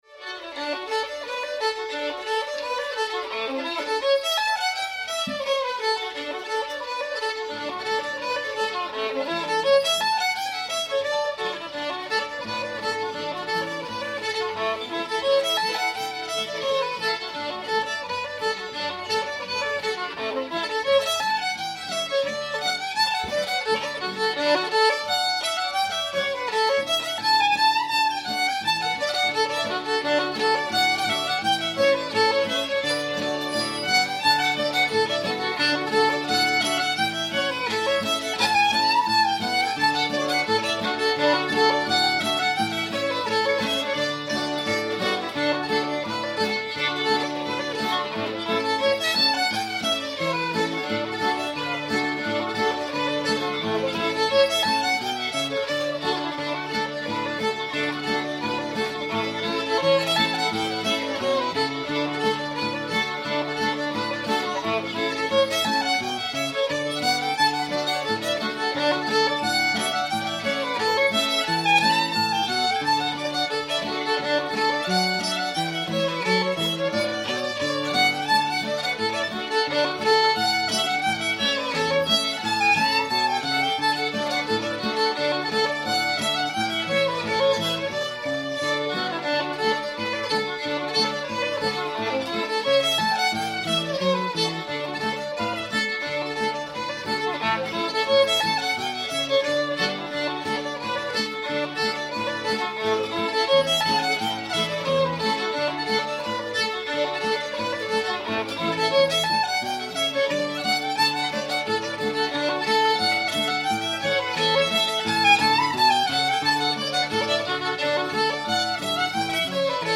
woodchopper's reel [D]